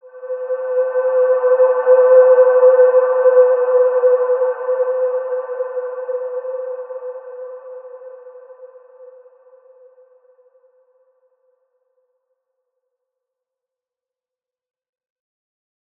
Large-Space-C5-mf.wav